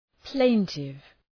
Προφορά
{‘pleıntıv}